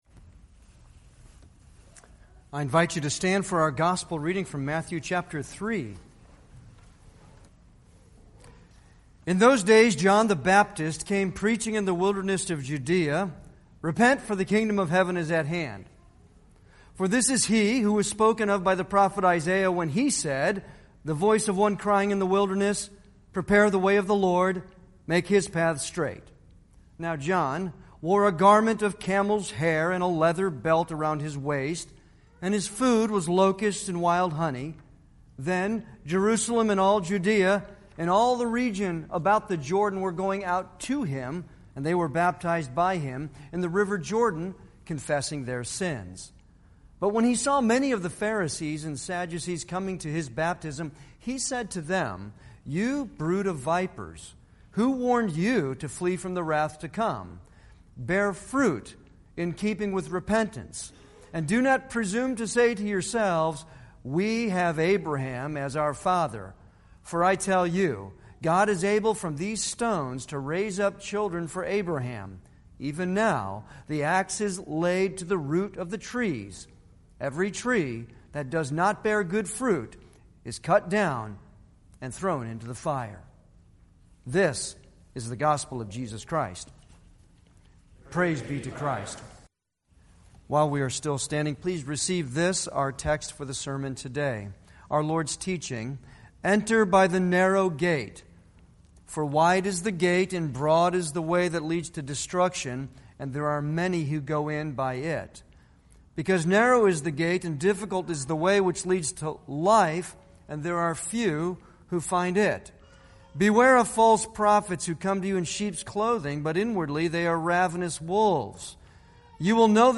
Passage: Matthew 7:13-23 Service Type: Sunday worship